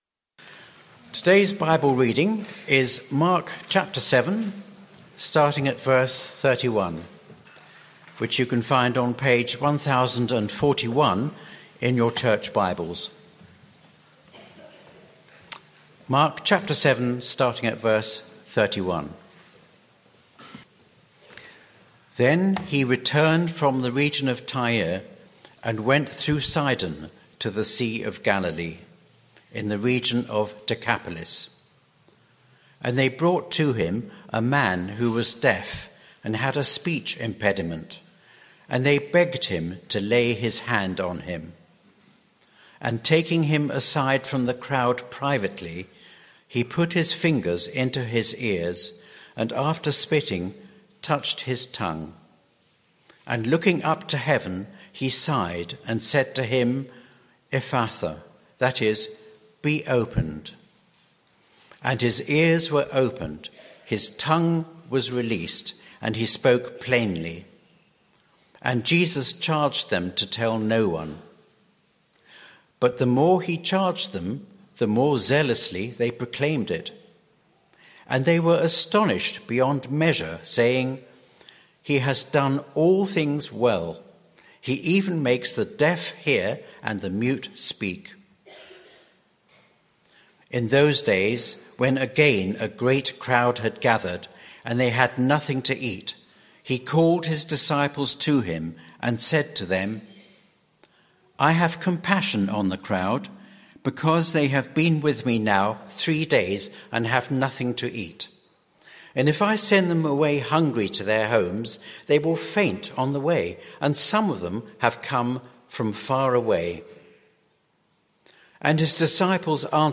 Sermon - Audio Only Search media library...